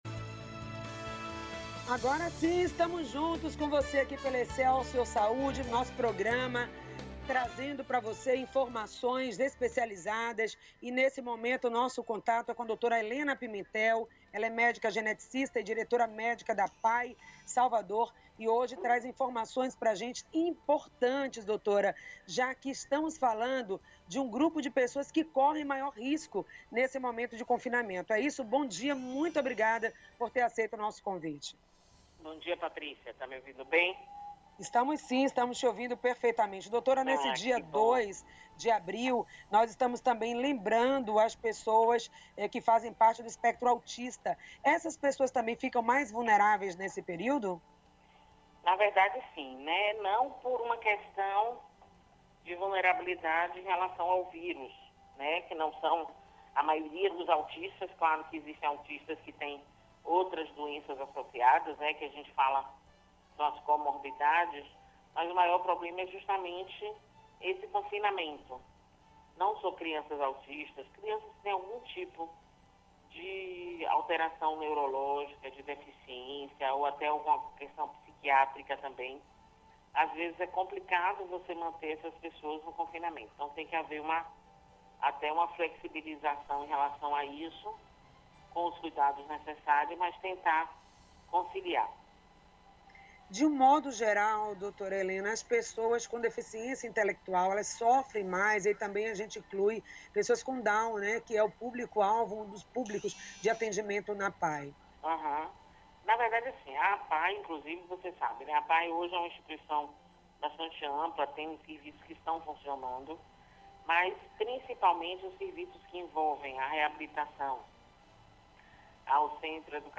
O Excelsior Saúde desta quinta-feira (02.04) abordou os impactos que da pandemia Covid 19 para pessoas com deficiência intelectual que são também do grupo de risco pela dificuldade de concentração e menor tolerância à reclusão. A entrevista